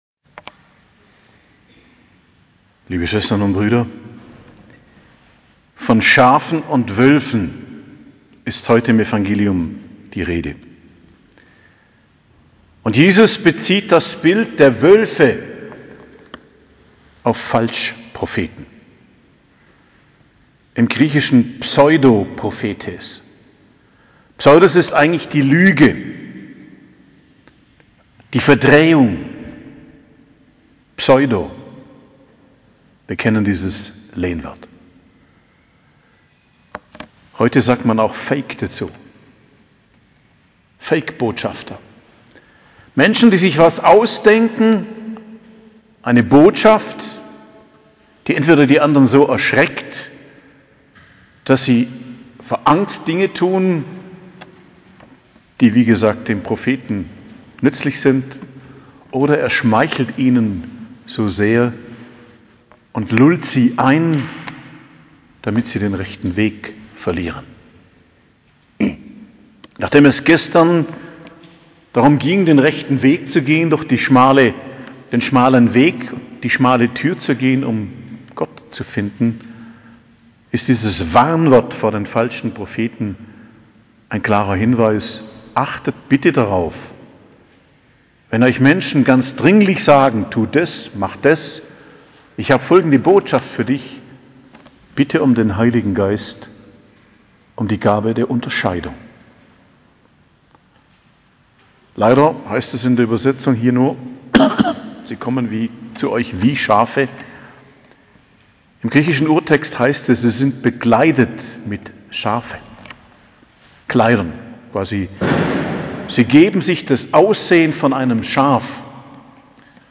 Predigt am Mittwoch der 12. Woche i.J., 22.06.2022